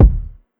Mozart Theme Kick.wav